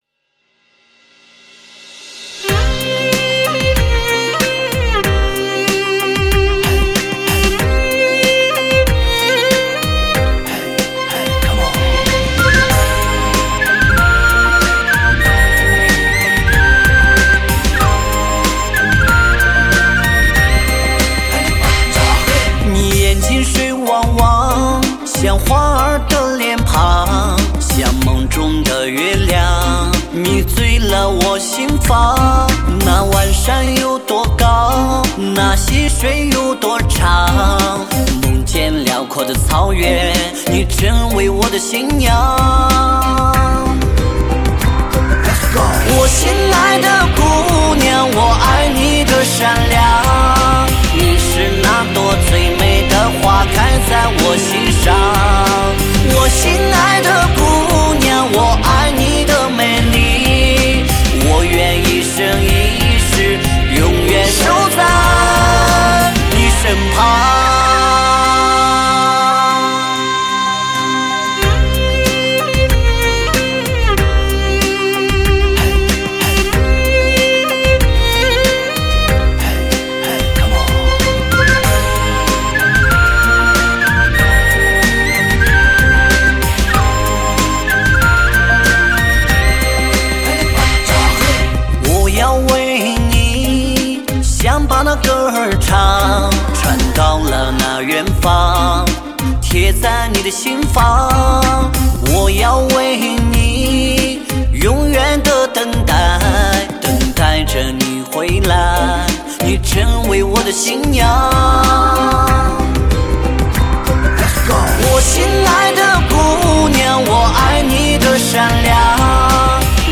原生态民族绝美天籁，无法抵御的音乐盛宴！